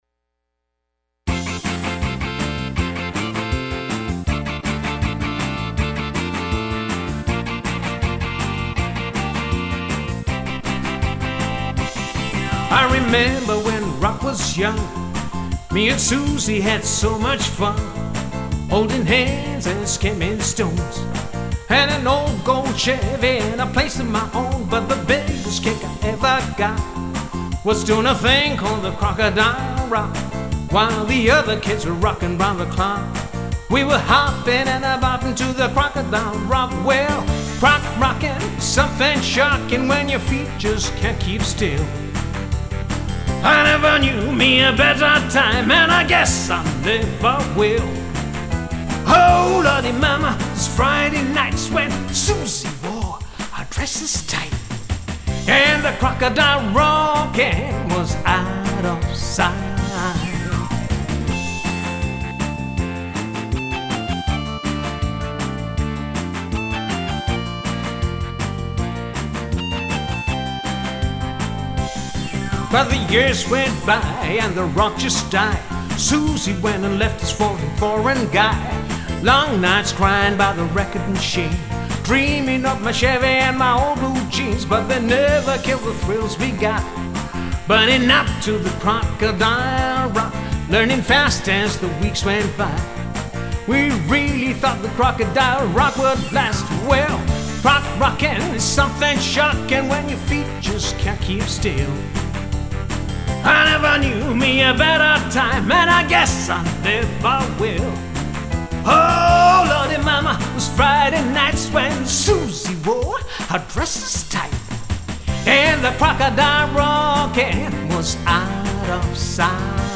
70’s & 80’s / Party Pop / Reggae / Hot Latin / Disco